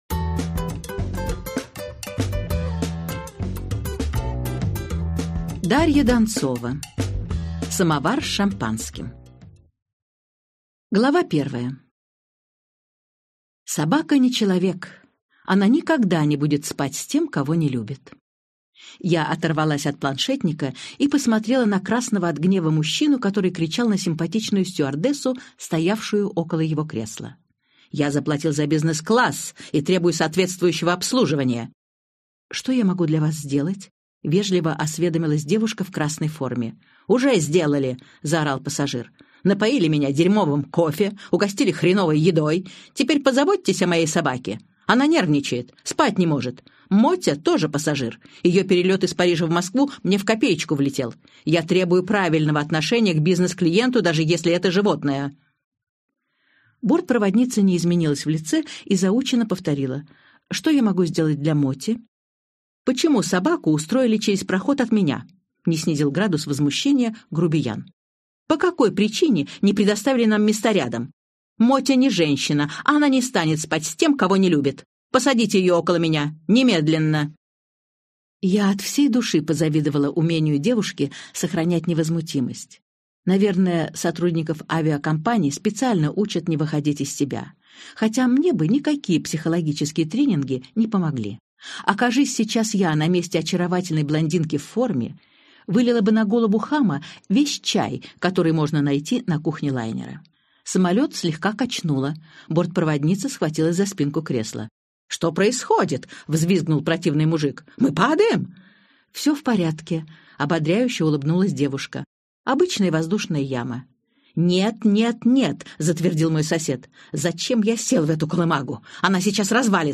Аудиокнига Самовар с шампанским - купить, скачать и слушать онлайн | КнигоПоиск